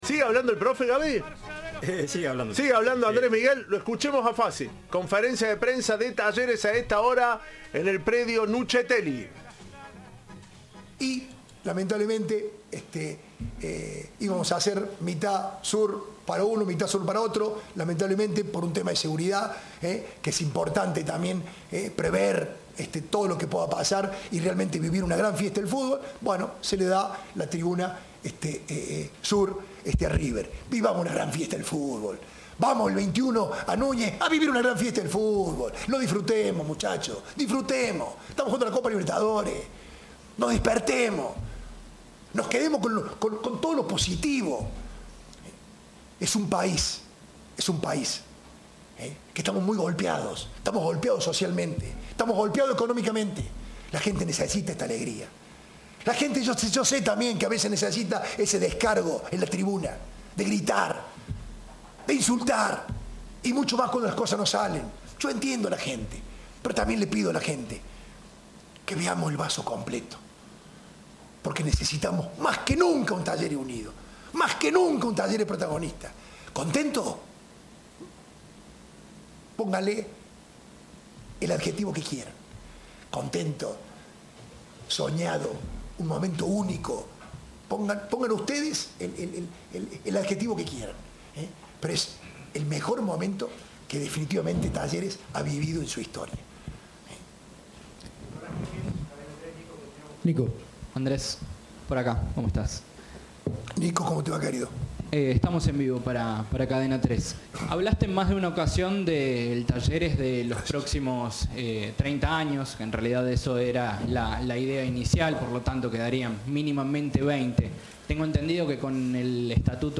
dialogó en conferencia de prensa